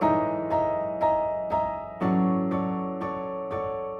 Index of /musicradar/gangster-sting-samples/120bpm Loops
GS_Piano_120-E1.wav